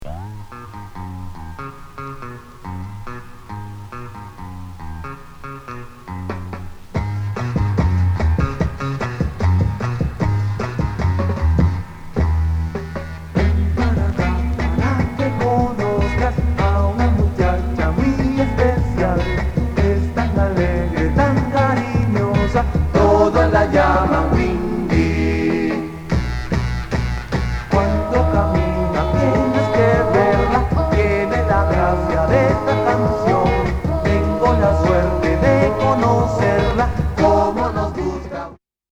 スパニッシュ、カントリー、R&B、カリビアンなの好き、